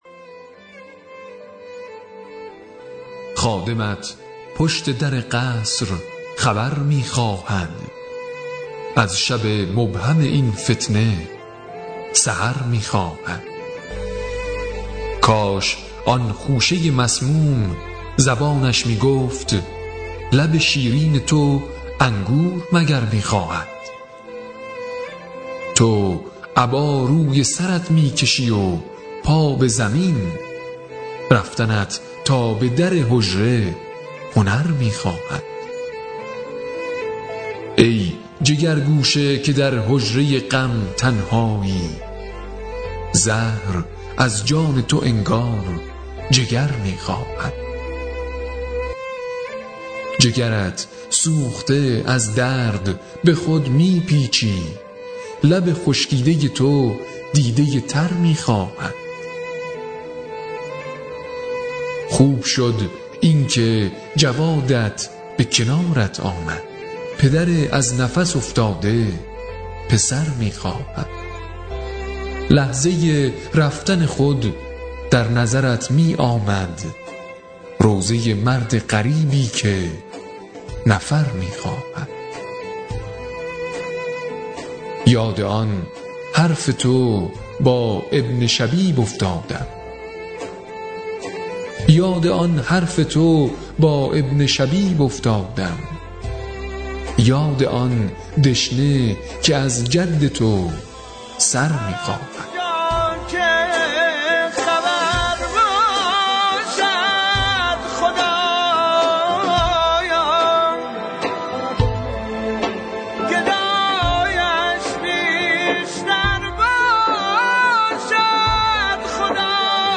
شعرخوانی| پدرِ از نفس افتاده پسر می‌خواهد